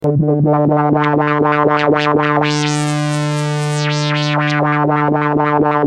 Free MP3 vintage Korg PS3100 loops & sound effects 7